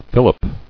[fil·lip]